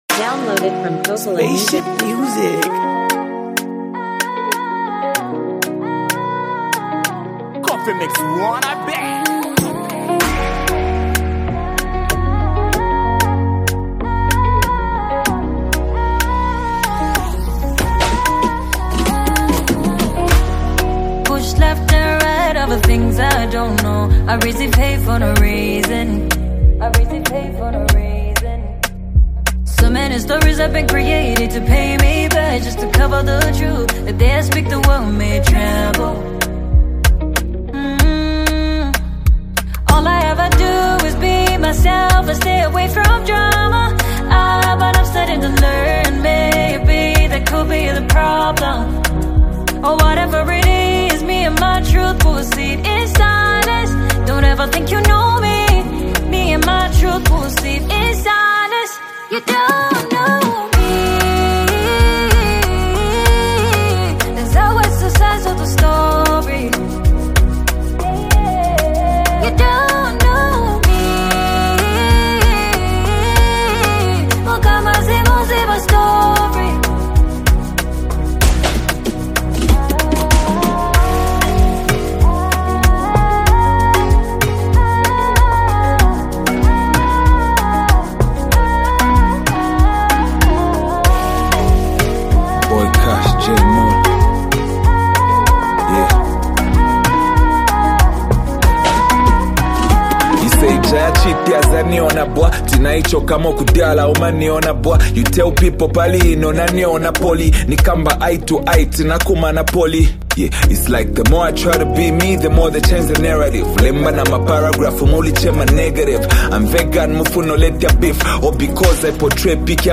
an emotional and heartfelt song